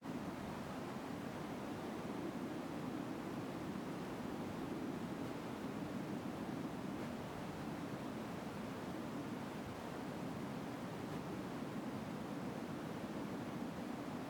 Fixed the sounds since they were seemingly empty, as well as a new more organic wind sound
wind.ogg